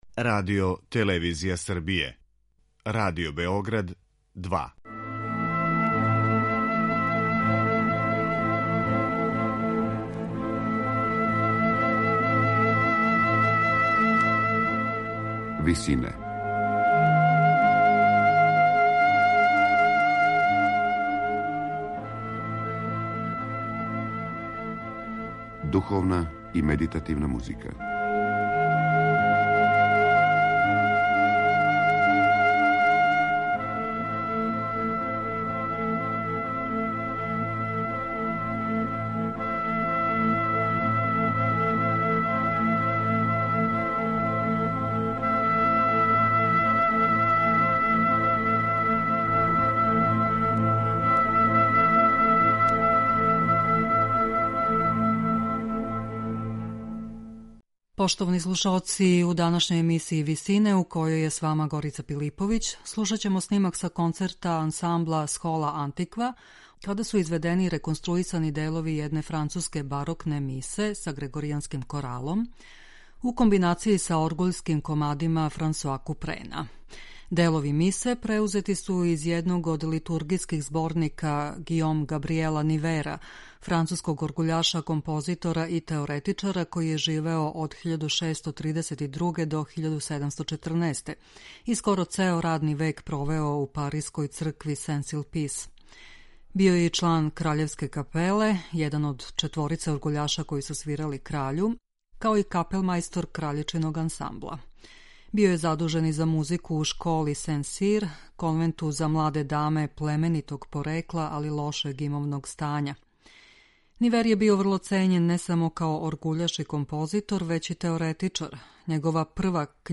грегоријанског корала
Поменути музичари су, у комбинацији са Купреновим оргуљским комадима, извели реконструисане делове једне француске барокне мисе са грегоријанским коралом.